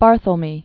(bärthəl-mē), Donald 1931-1989.